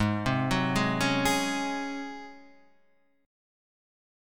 G# 11th